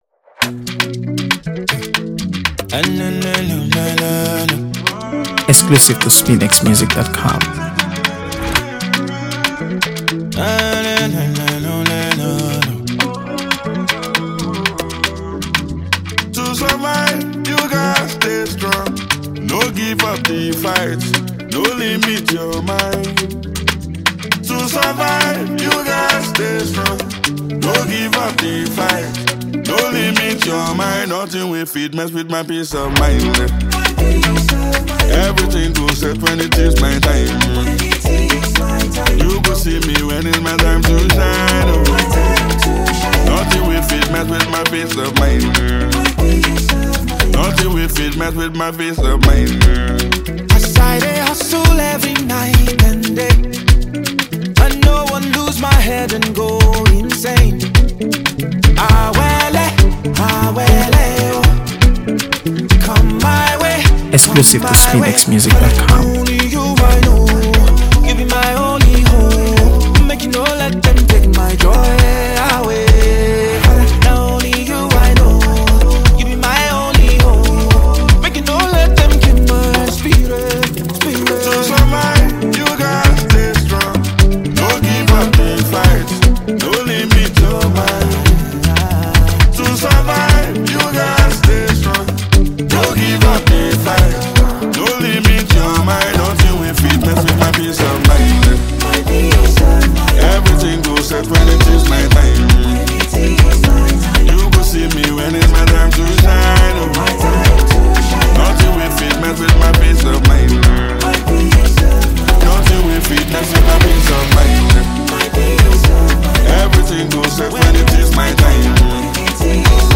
AfroBeats | AfroBeats songs
Blissful Harmonies
soulful single
emotive vocals
Afro-soul